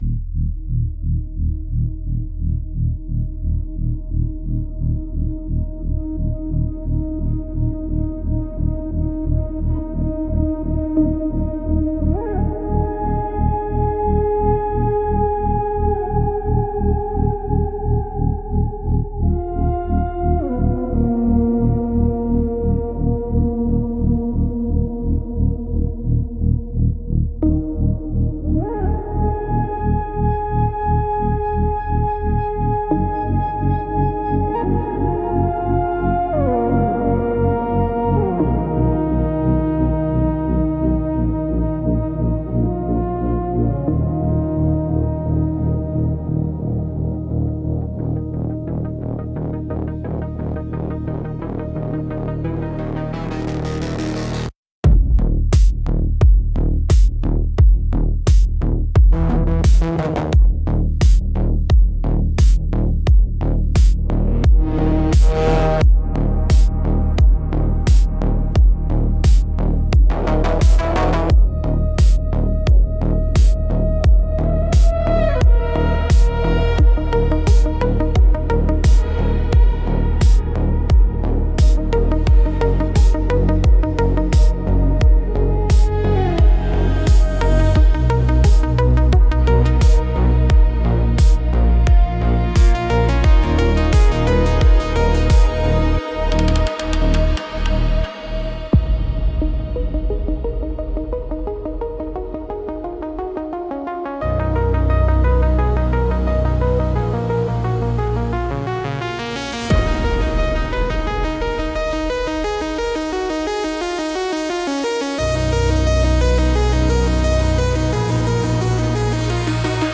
Instrumentals - The Slow Unmaking